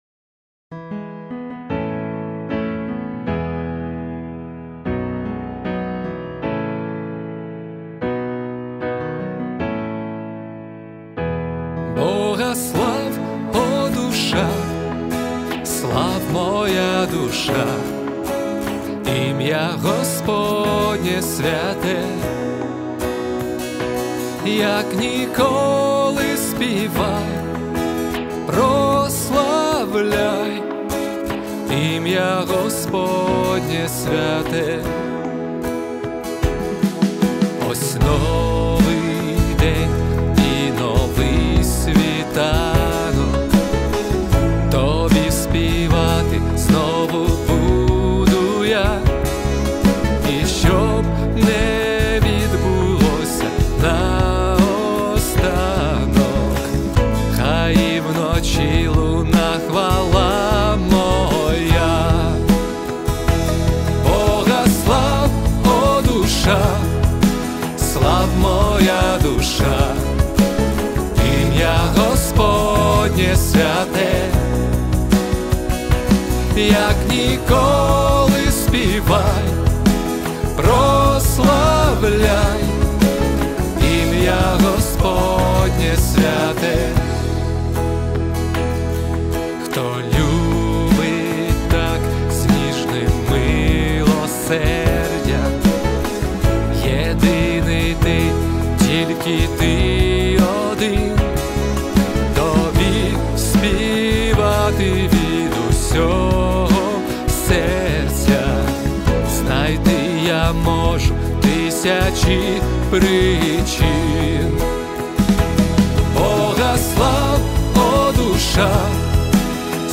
песня
112 просмотров 126 прослушиваний 9 скачиваний BPM: 76